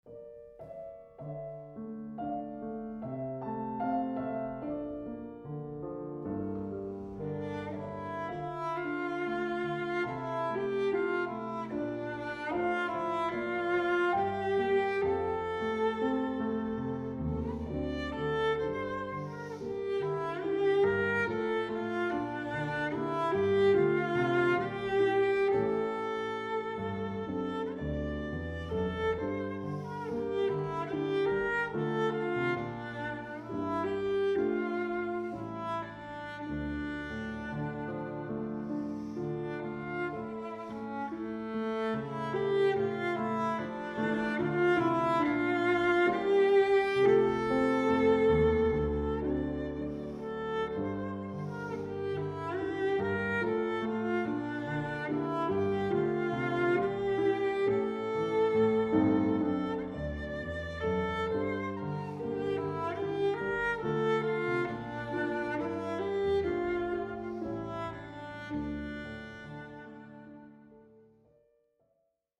Cello
Klavier